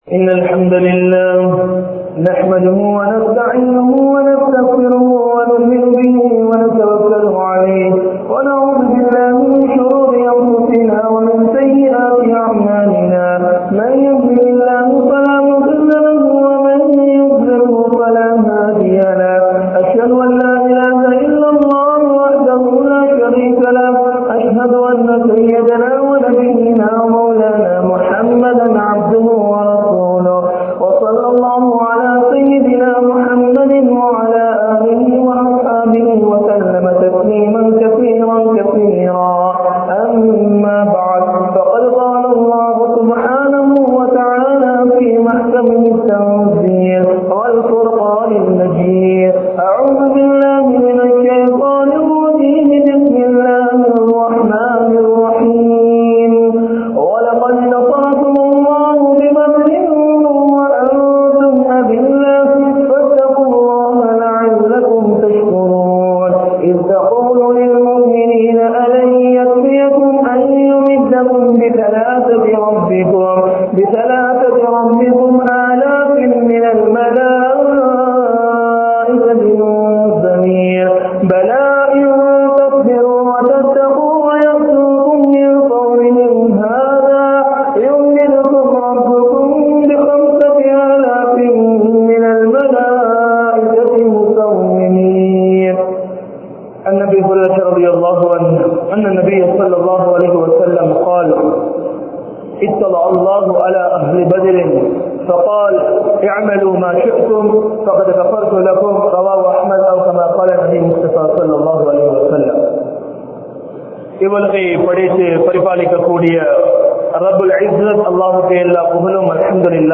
Paakkiyam Niraintha Badhur Sahabaakkal (பாக்கியம் நிறைந்த பத்ர் ஸஹாபாக்கள்) | Audio Bayans | All Ceylon Muslim Youth Community | Addalaichenai
Muhiyadeen Jumua Masjidh